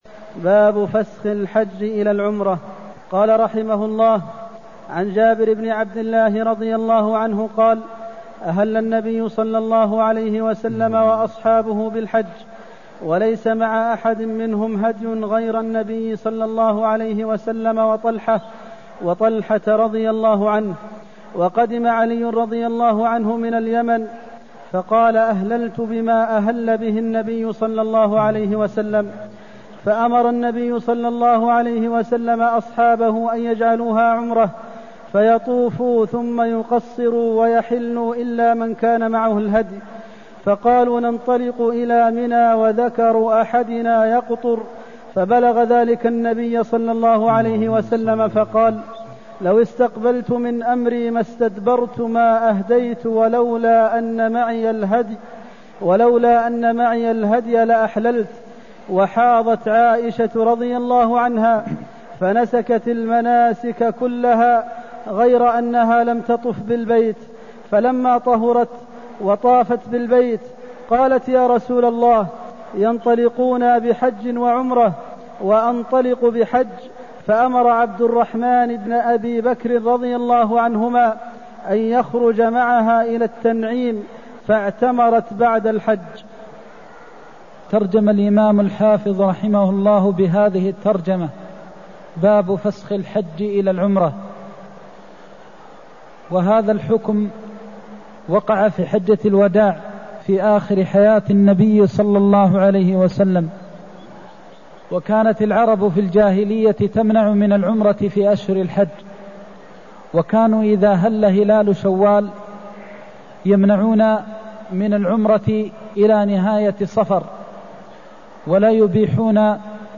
المكان: المسجد النبوي الشيخ: فضيلة الشيخ د. محمد بن محمد المختار فضيلة الشيخ د. محمد بن محمد المختار جواز فسخ الحج إلى عمرة لمن لم يسق الهدي (230)